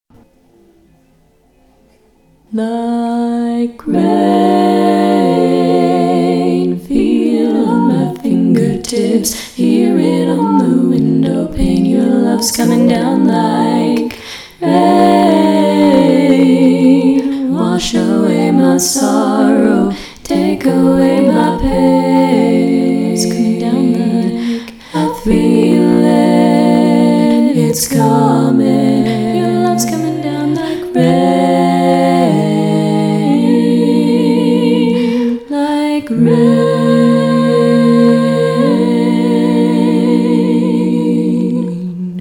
How many parts: 4
:-P I also apologize for the quality of the tracks.
All Parts mix: